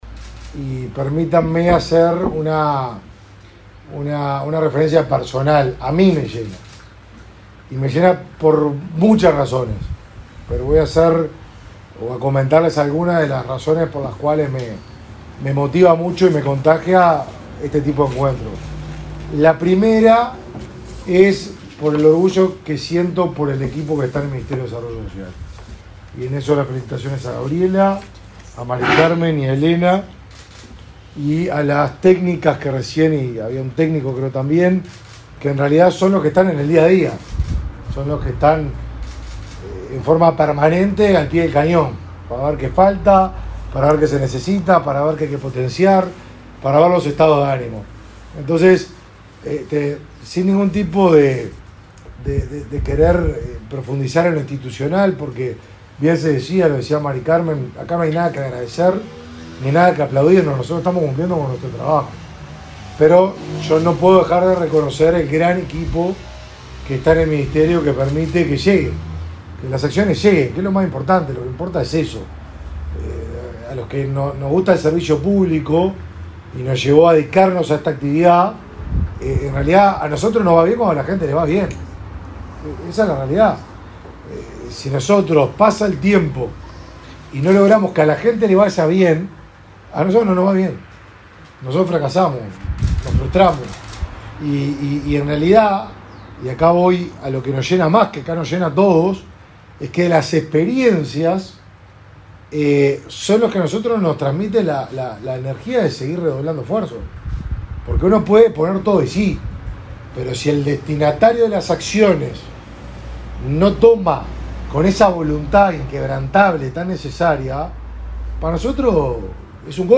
Palabras del ministro de Desarrollo Social, Martín Lema
El ministro de Desarrollo Social, Martín Lema, participó de la entrega de certificados del programa Accesos en Ciudad de la Costa, Canelones.